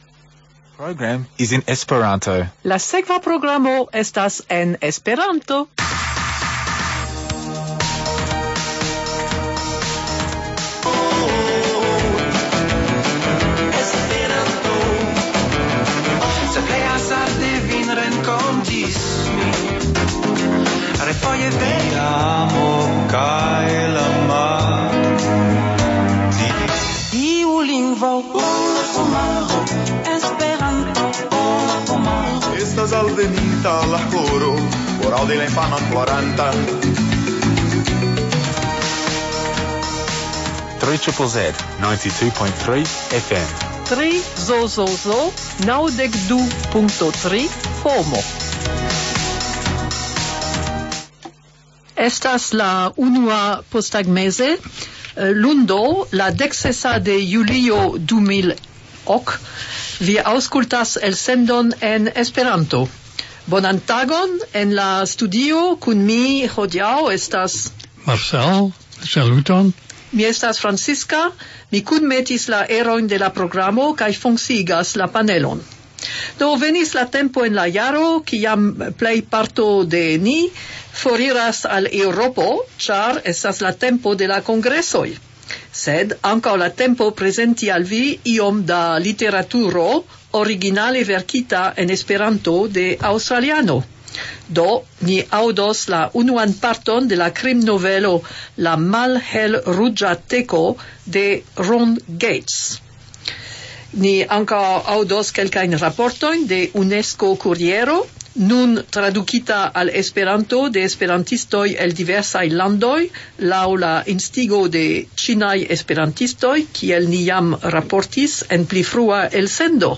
Legado